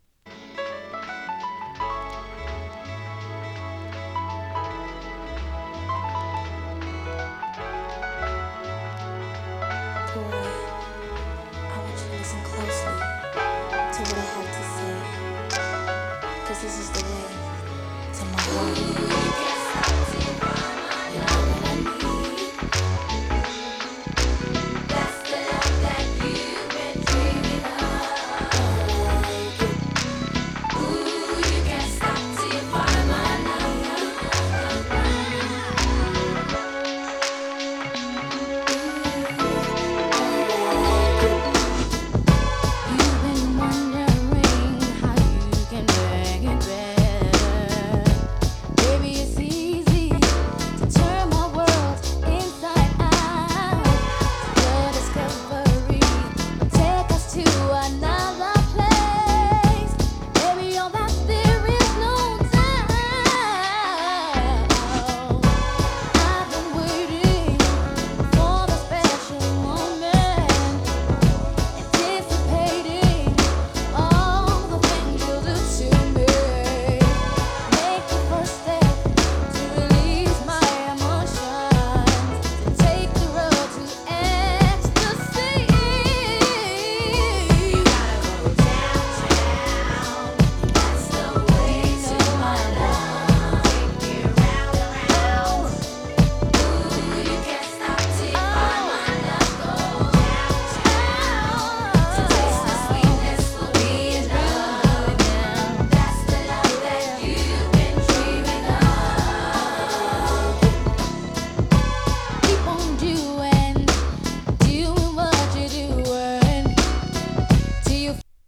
90s RnB
90sに活躍したガールズ・トリオによる初期のヒット曲。